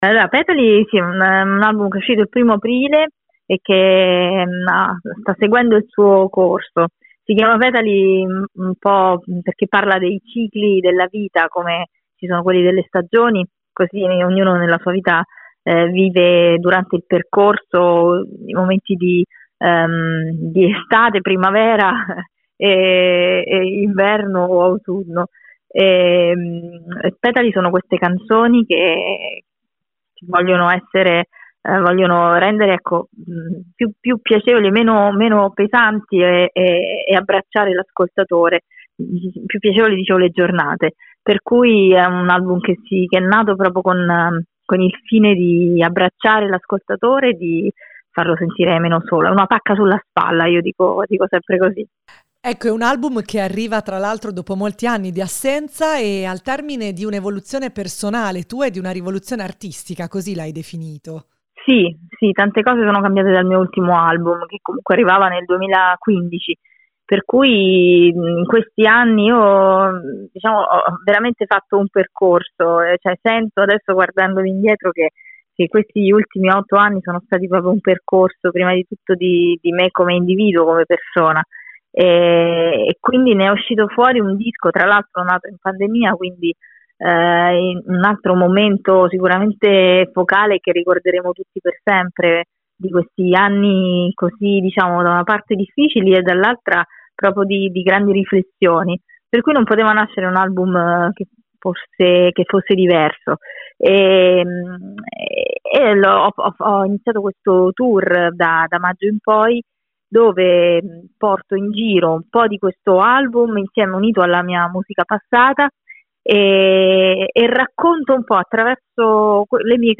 Sentiamo l’artista raggiunta al telefono